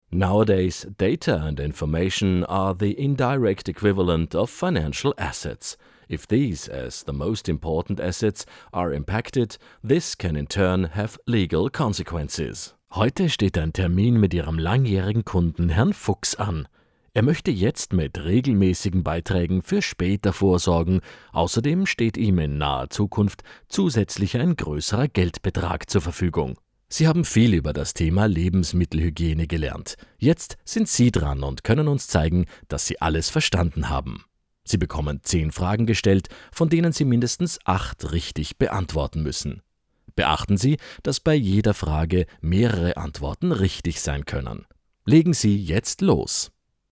Stimme für Imagevideo, POS-Zwecke & Intranet
Beispiele zum Thema Intranet bzw. E-Learing (DE-EN):